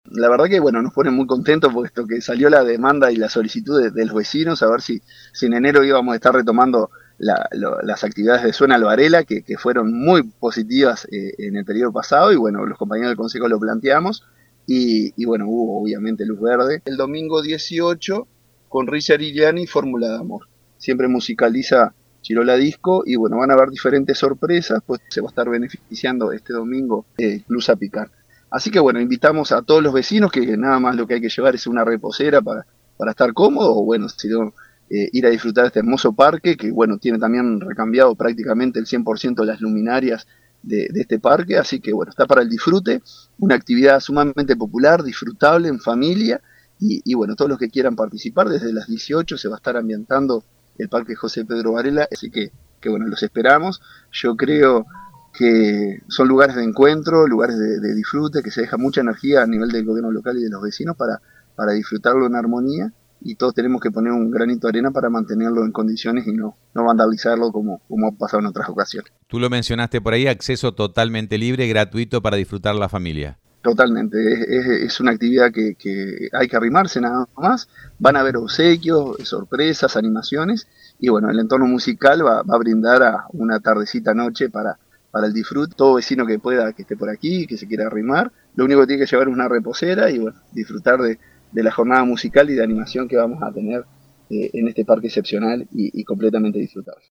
Escuchamos al alcalde, Marcelo Alonso, brindando detalles de la actividad…